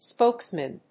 発音記号
• / ˈspoksmɪn(米国英語)
• / ˈspəʊksmɪn(英国英語)
spokesmen.mp3